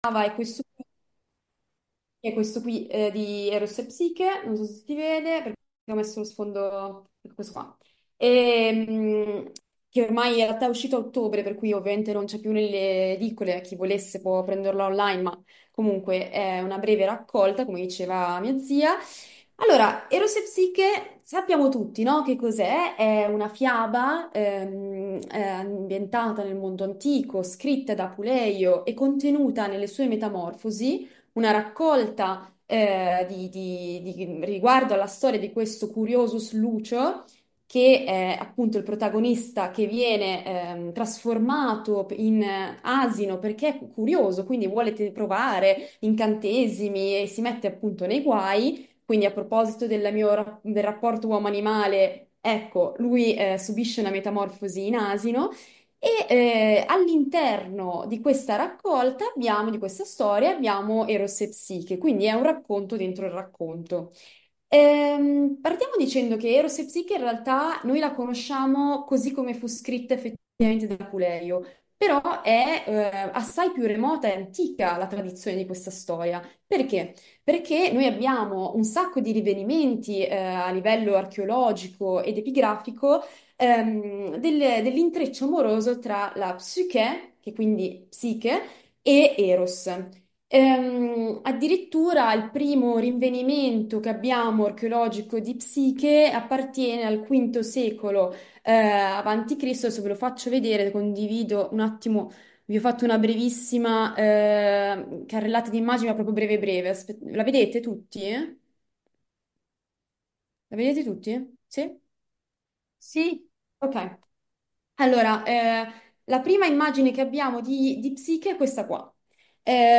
Ecco la lettura di questi bellissimi versi dell’Inno a Venere dal De rerum natura di Lucrezio da parte dei nostri soci filologi e classicisti